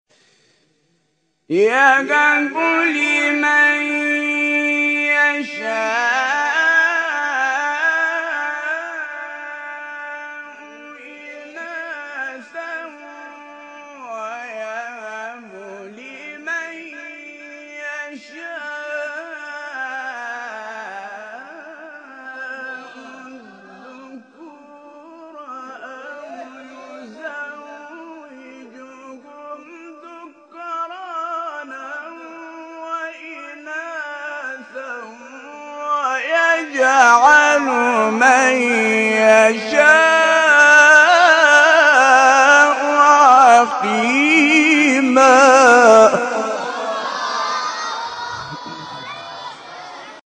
گروه شبکه اجتماعی: فرازهای صوتی از تلاوت قاریان بنام و ممتاز کشور را می‌شنوید.